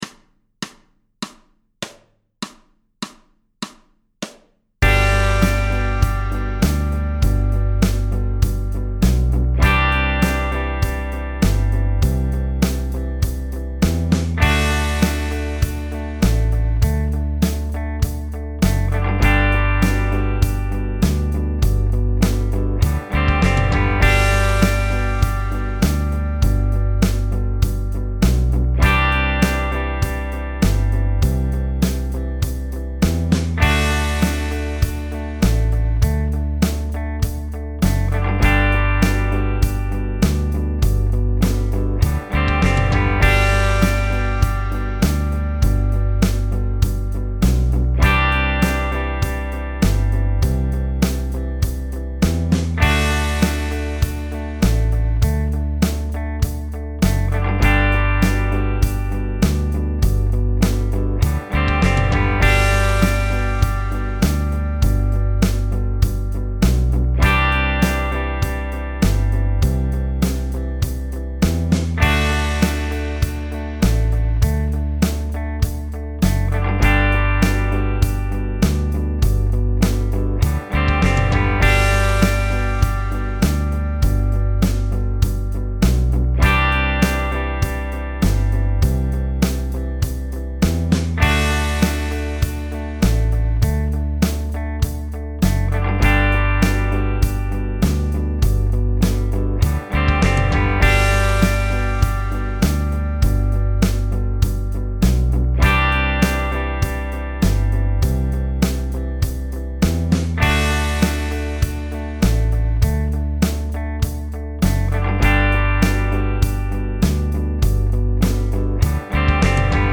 The jam track for this episode moves through two measures of G major, two measures of C major, two measures of D major, and then two more measures of G major.